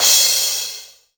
DX crash.wav